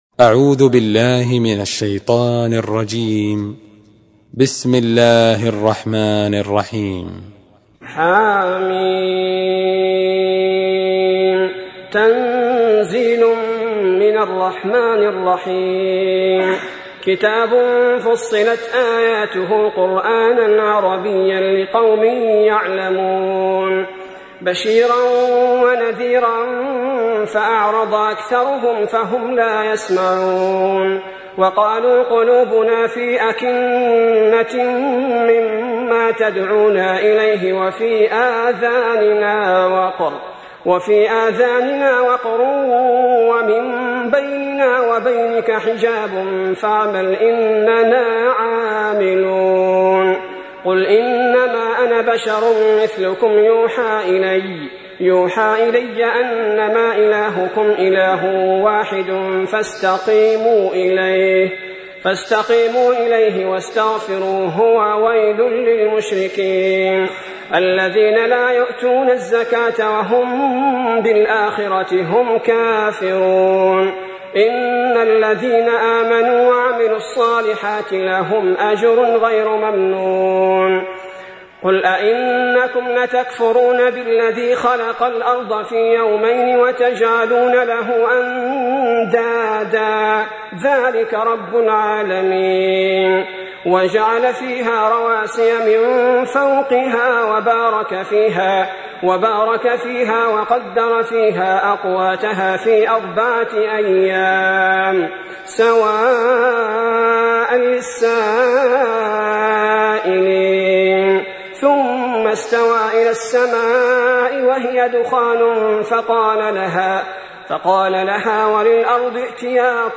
تحميل : 41. سورة فصلت / القارئ عبد البارئ الثبيتي / القرآن الكريم / موقع يا حسين